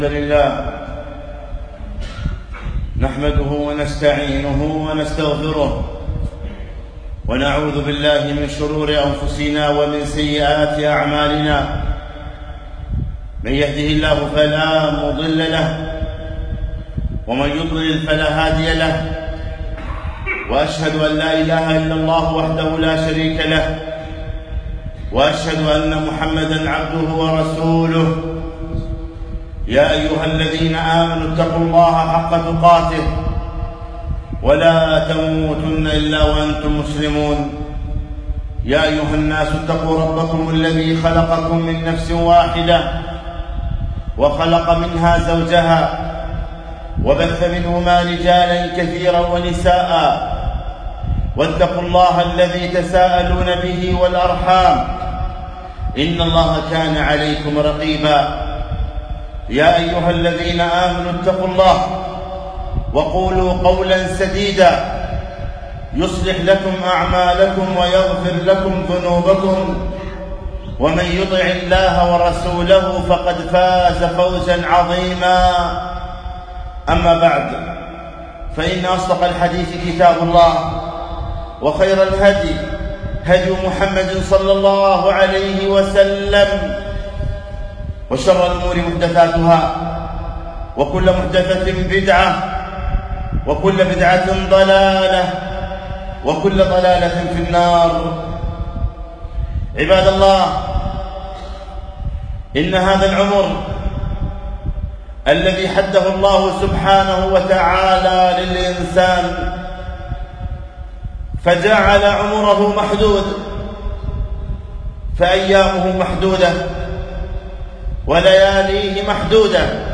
خطبة - أنفاس لاتعود